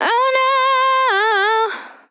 Categories: Vocals Tags: dry, english, female, fill, LOFI VIBES, No, OH, sample